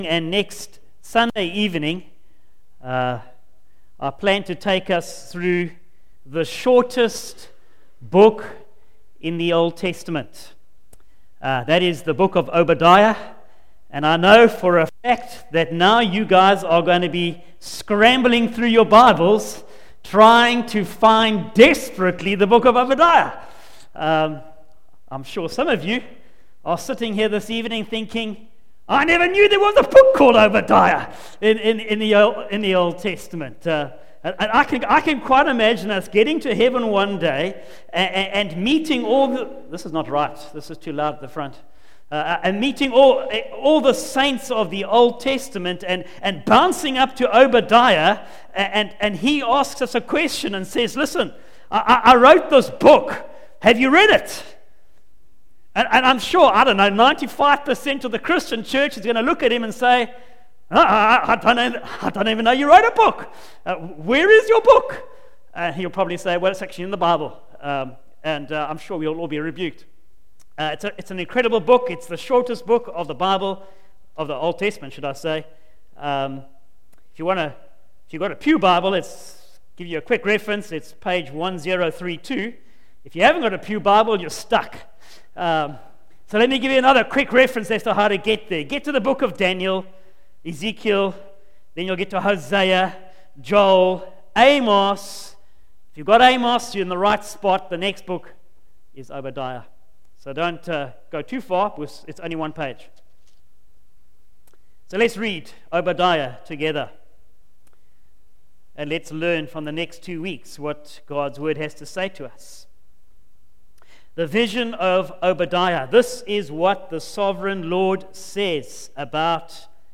Facebook Twitter email Posted in Evening Service